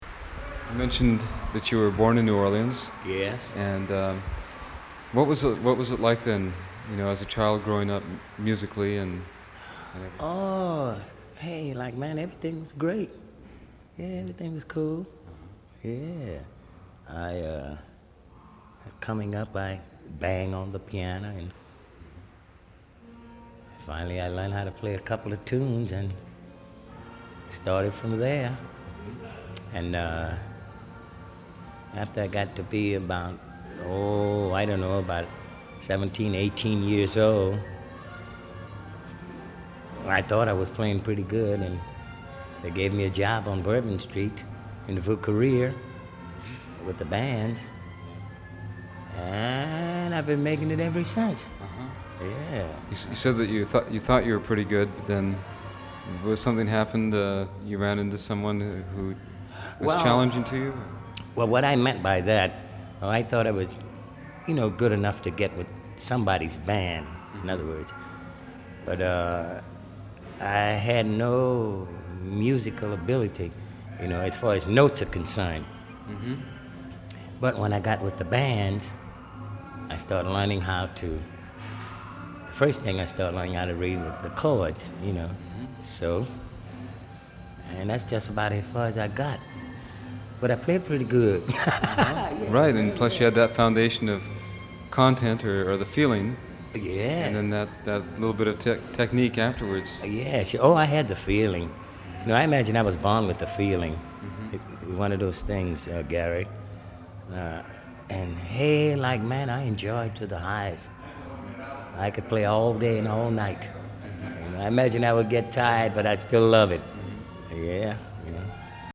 Interviews | Project Jazz Verbatim